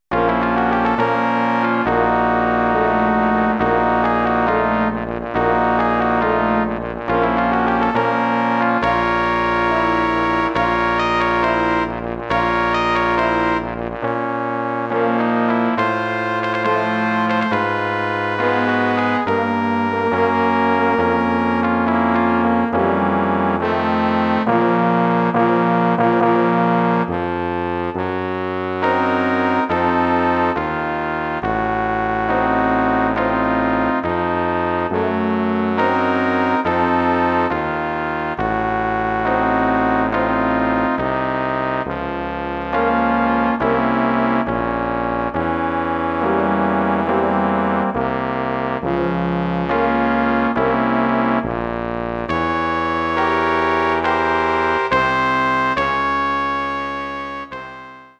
A Memorial March (1919)
Bearbeitung für Blechbläserquintett
Besetzung: 2 Trompeten, Horn, Posaune, Tuba
arrangement for brass quintet
Instrumentation: 2 trumpets, horn, trombone, tuba